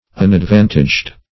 unadvantaged - definition of unadvantaged - synonyms, pronunciation, spelling from Free Dictionary
unadvantaged.mp3